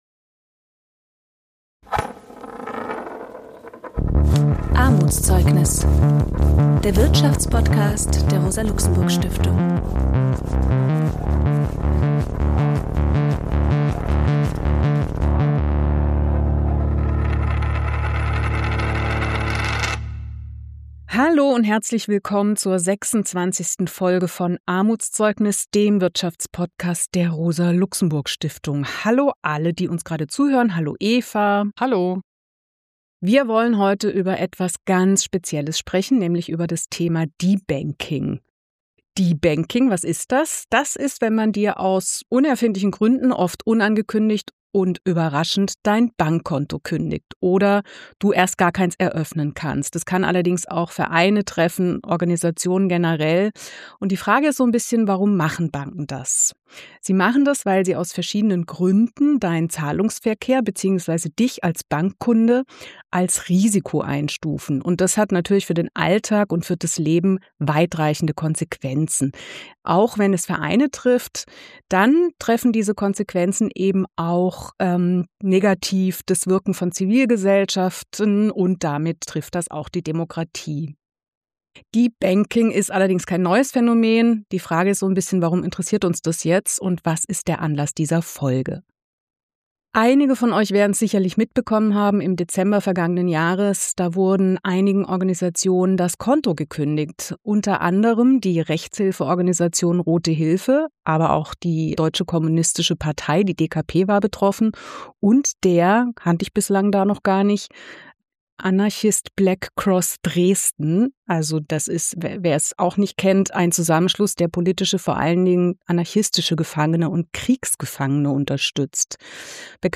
Wir beleuchten die Hintergründe von debanking und haben mit einer Betroffenen gesprochen.